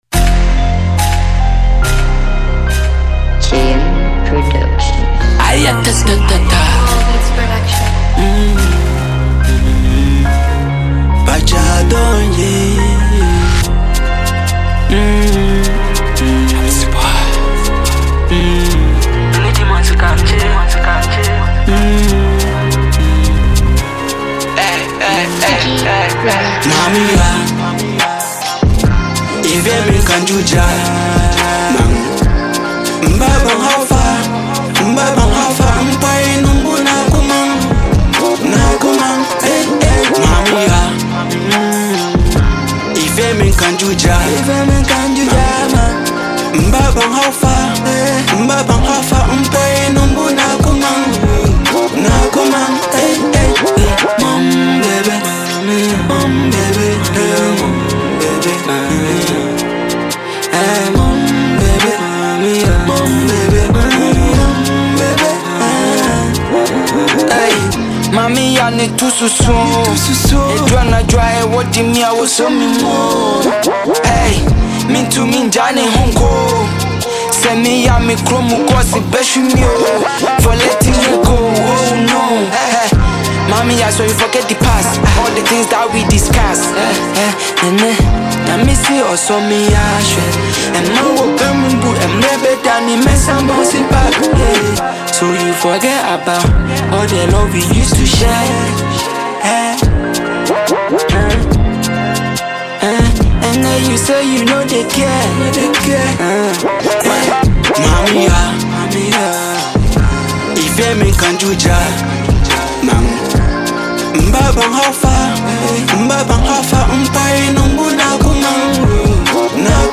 Get ready to groove to the infectious beats